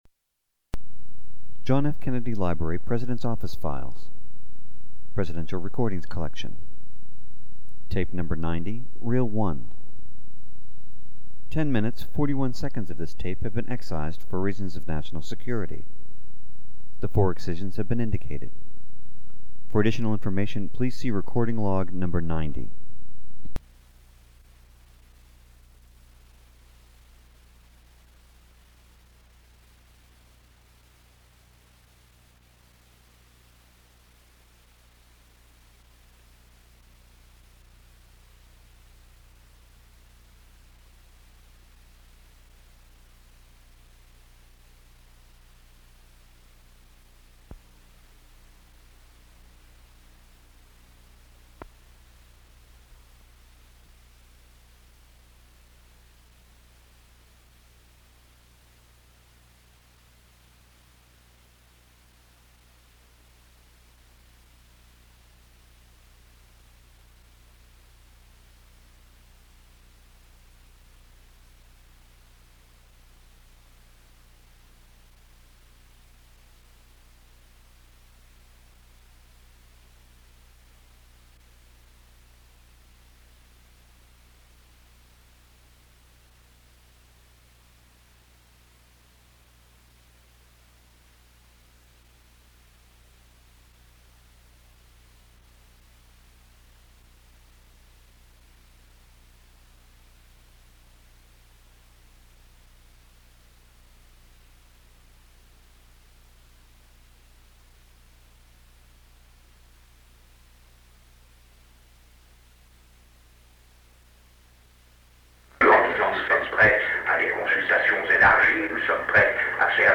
Sound recording of part of a meeting held on May 28, 1963, between President John F. Kennedy and Belgian Minister of Foreign Affairs Paul-Henri Spaak.
They continue to discuss relations between the United States and Europe, the Multilateral Force (MLF), and President Kennedy’s upcoming trip to Europe. Mr. Spaak speaks in French and is the main speaker at the meeting. President Kennedy’s interpreter can be heard only in whispers, making it difficult to discern any content. Mainly office noises and hallway conversations follow the meeting for about 14 minutes.